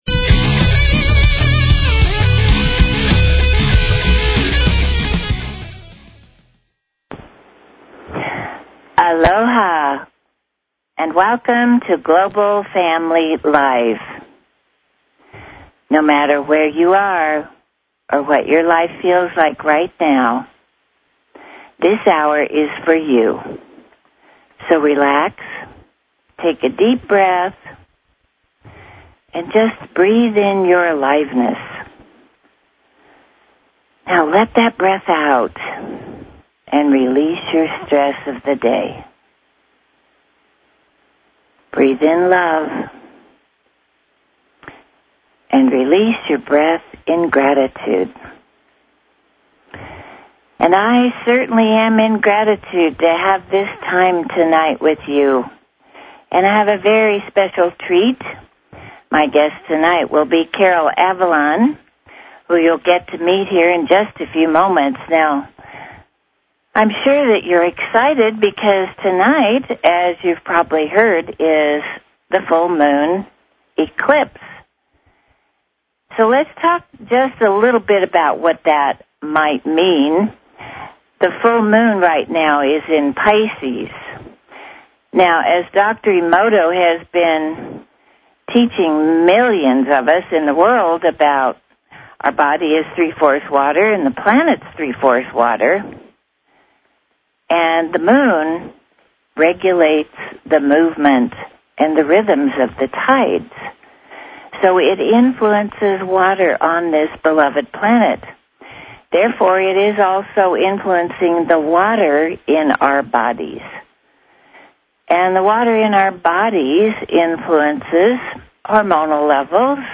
Talk Show Episode, Audio Podcast, Global_Family_Live and Courtesy of BBS Radio on , show guests , about , categorized as